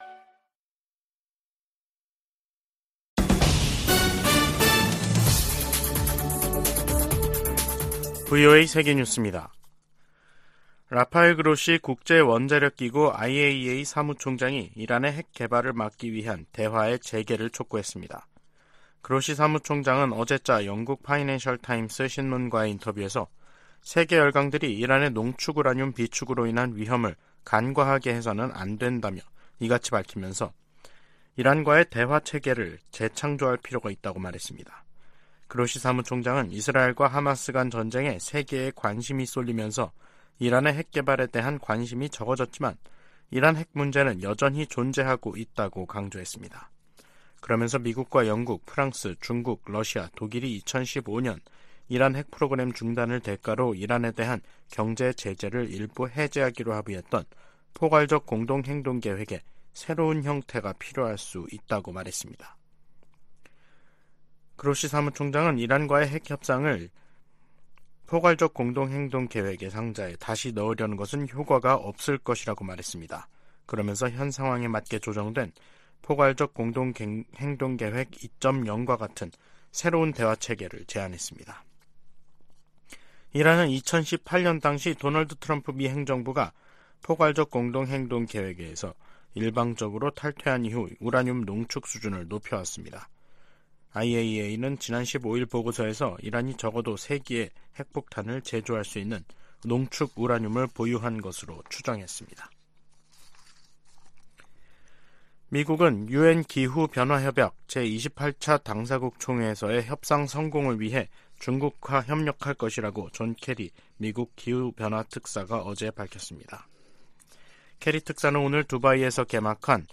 VOA 한국어 간판 뉴스 프로그램 '뉴스 투데이', 2023년 11월 30일 2부 방송입니다. 미국 재무부 해외자산통제실이 북한의 해킹 자금을 세탁한 가상화폐 믹서 업체 '신바드'를 제재했습니다. 북한이 제도권 금융기관에서 암호화폐 인프라로 공격 대상을 변경했다고 백악관 고위 관리가 밝혔습니다. 북한-러시아 군사 협력에 제재를 부과하도록 하는 법안이 미 하원 외교위원회를 만장일치 통과했습니다.